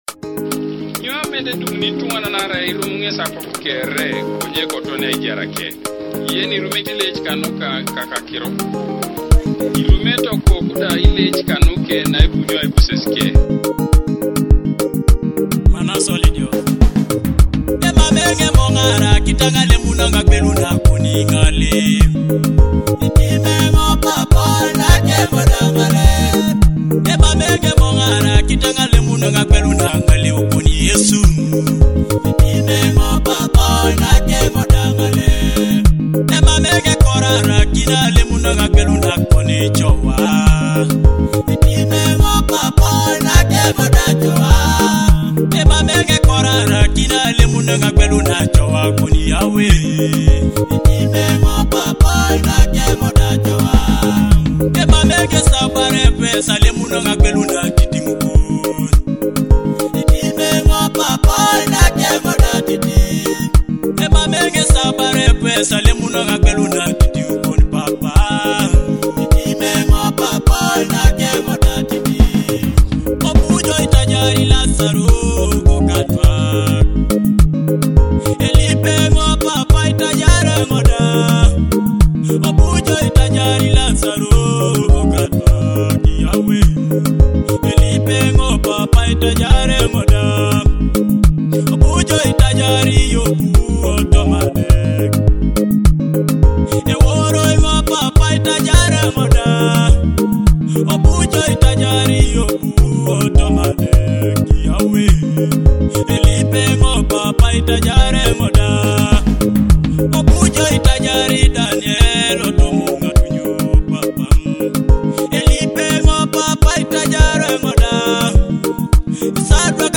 a heartfelt Teso gospel prayer for forgiveness.